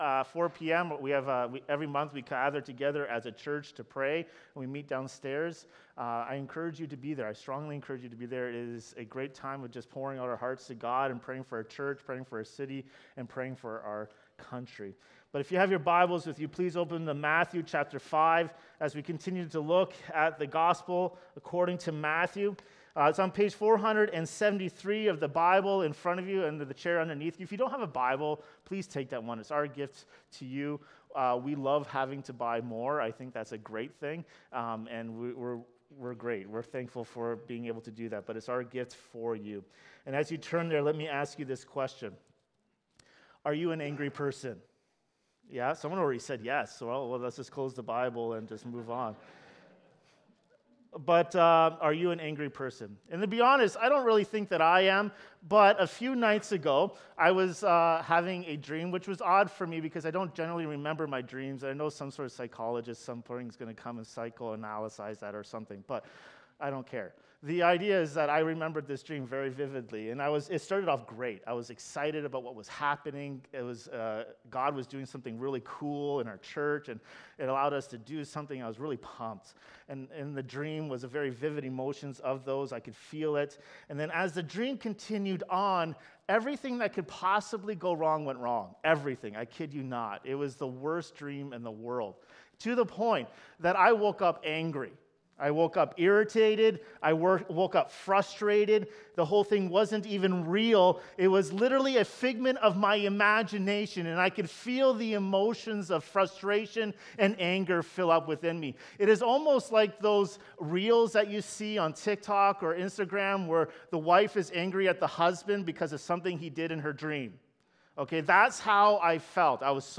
Anger & Reconciliation | Matthew 5:21–26 Sermon